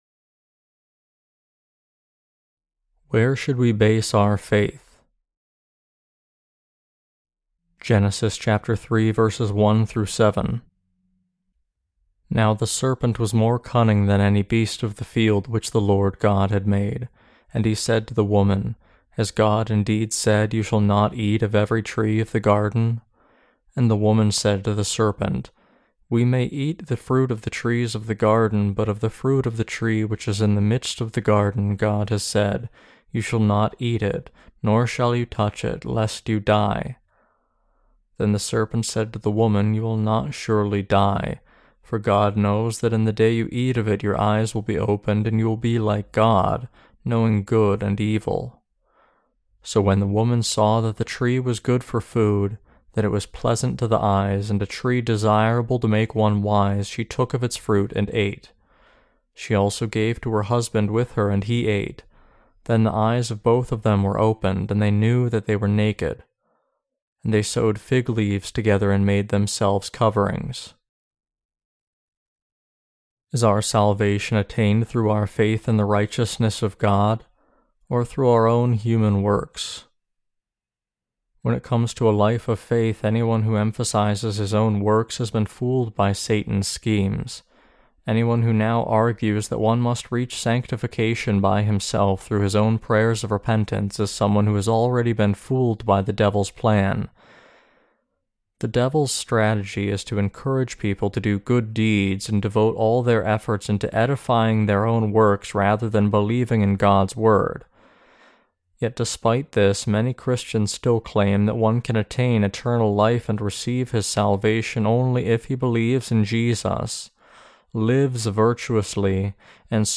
Sermons on Genesis (II) - The Fall of Man and The Perfect Salvation of God Ch3-3.